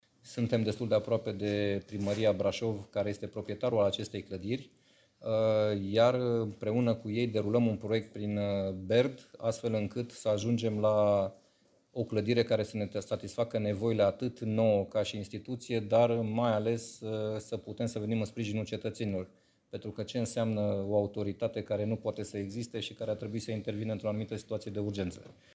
Colonelul Lucian Marciu: